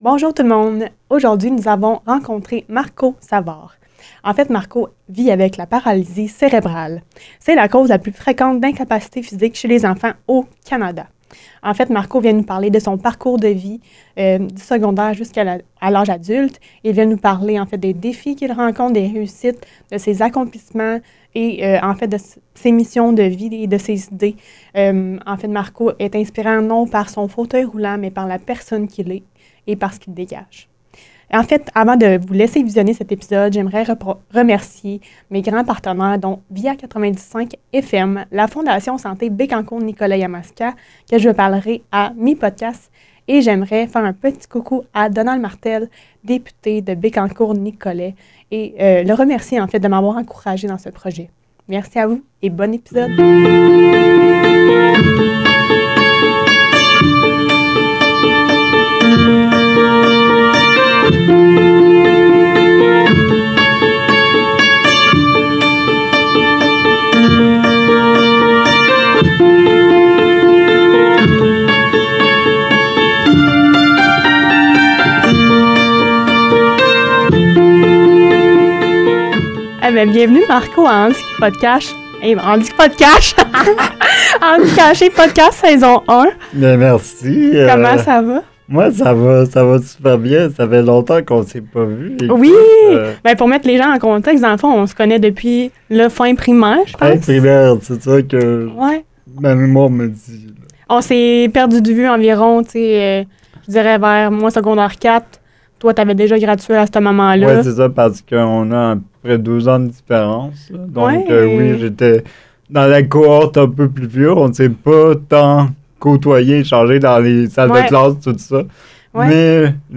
Un échange qui met en lumière la force intérieure et la capacité à transformer les défis en opportunités de croissance.